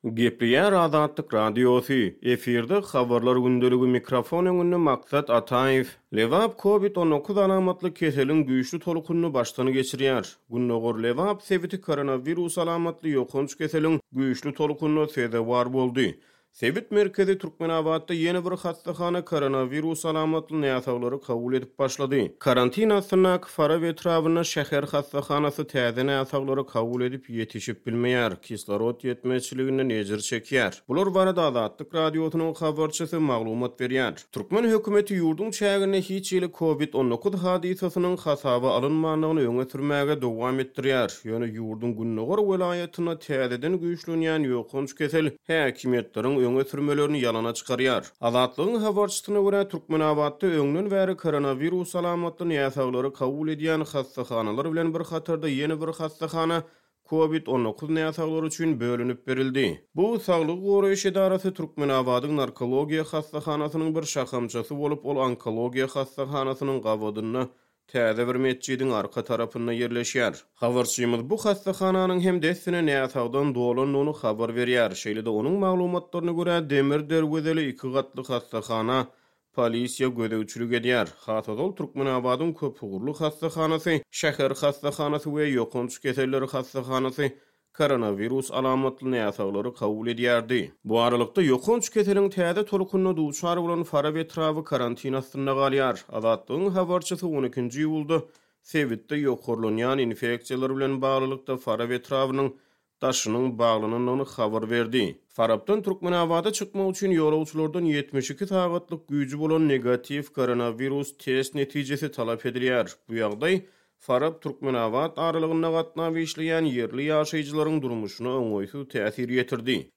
Bular barada Azatlyk Radiosynyň habarçysy maglumat berýär.